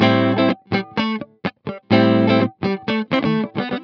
13 GuitarFunky Loop A.wav